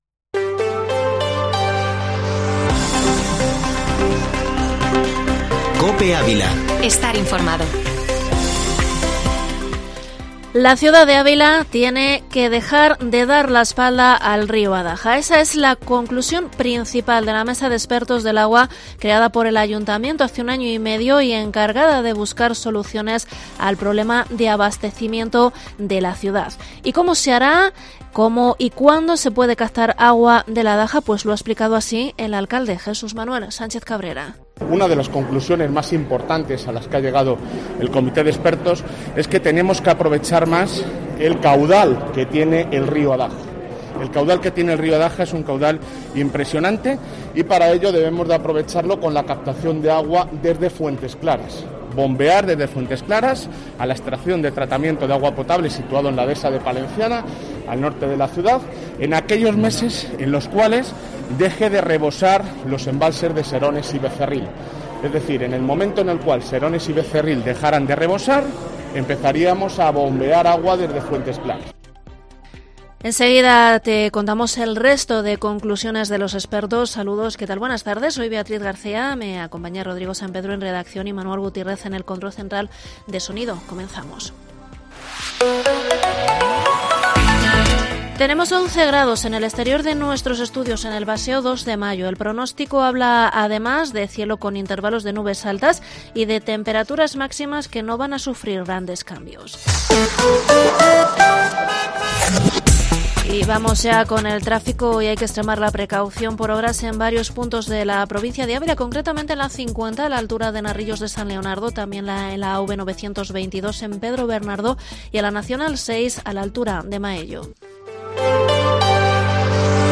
Informativo Mediodía COPE en Ávila 12/11/21